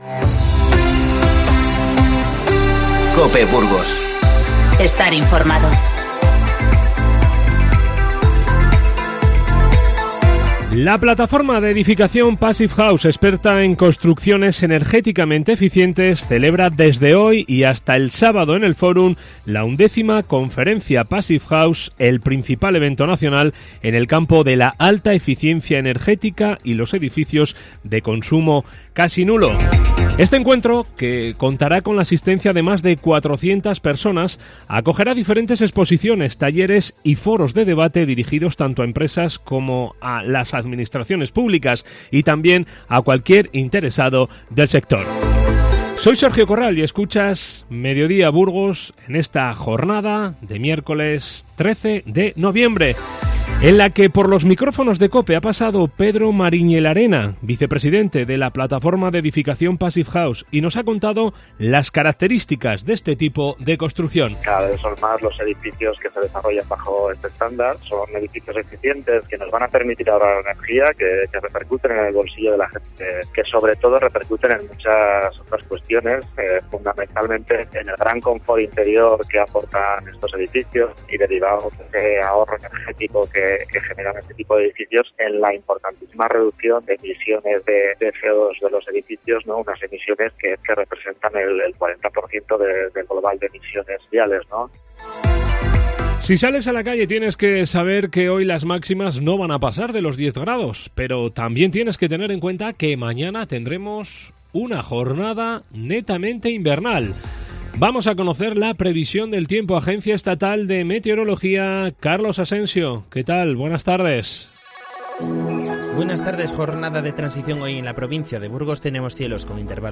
INFORMATIVO Mediodía 13-11-19